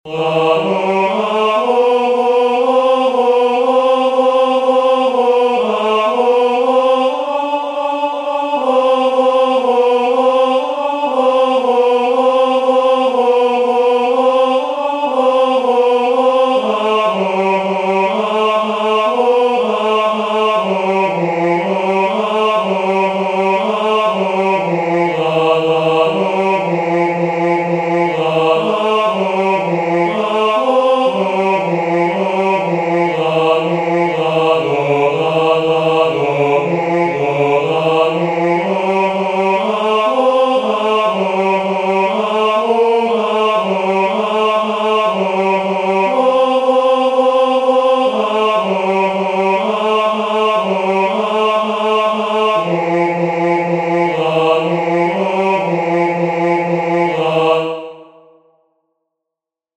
"Dum steteritis," the third responsory from the first nocturn of Matins, Common of Apostles